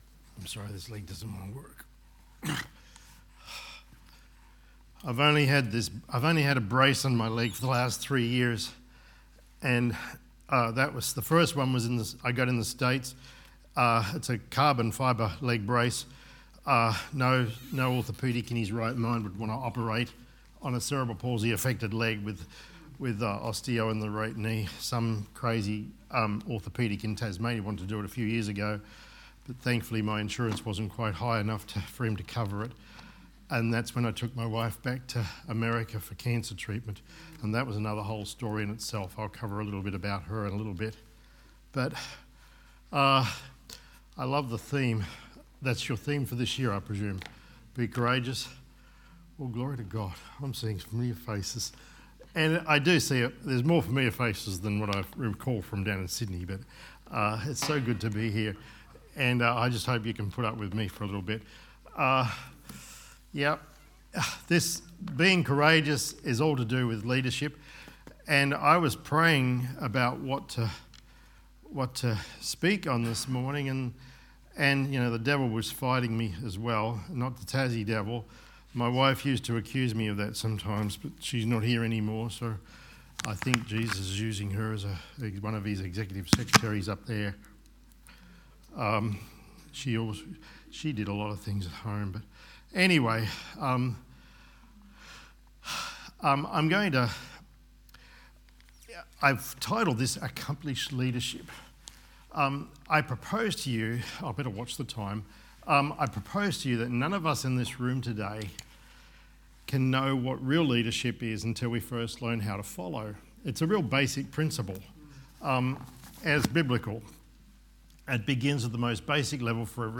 Sermons | Good Shepherd Baptist Church
Leadership Conference 2025